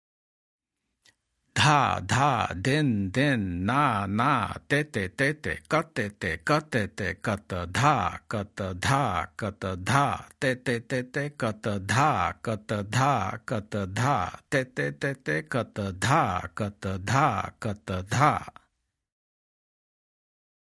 Demonstrations
Spoken